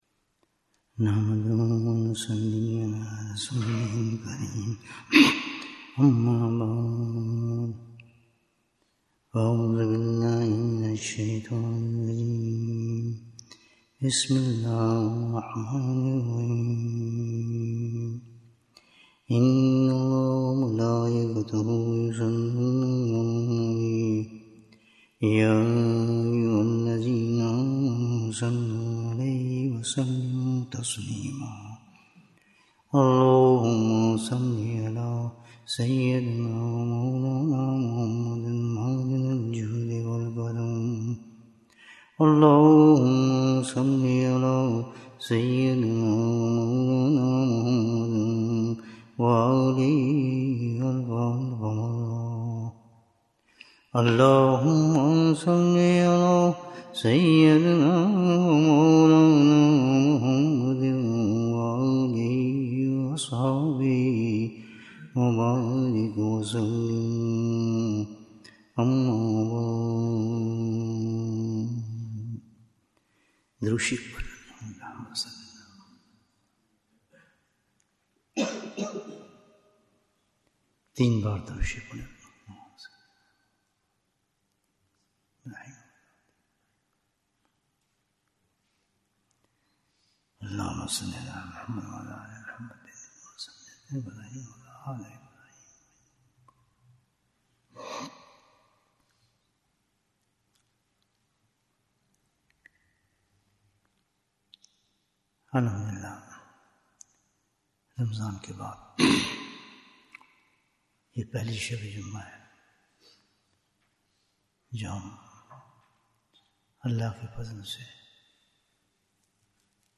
Bayan, 52 minutes 3rd April, 2025 Click for English Download Audio Comments What is the Special Message of Ramadhan? Ramadan brought us this message that deen is not restricted to a time period.